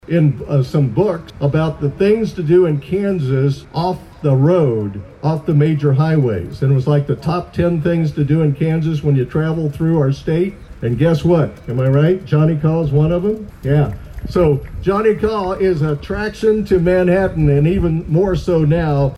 Former mayor Ed Klimek also spoke to the crowd about the refurbished Johnny Kaw statue